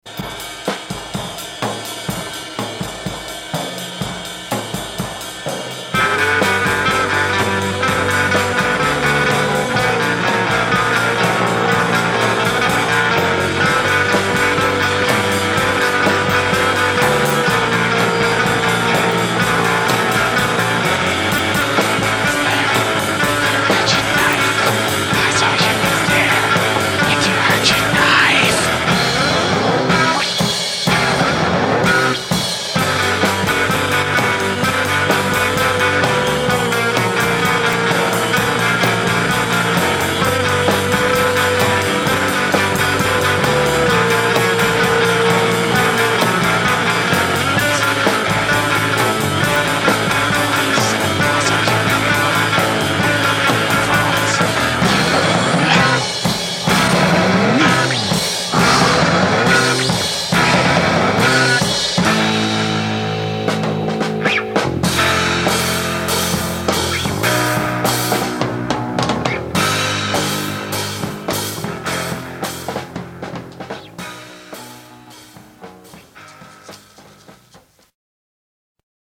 Music therapy gone awry.
Weird and strangely poignant.